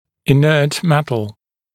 [ɪ’nɜːt ‘metl][и’нё:т ‘мэтл]инертный металл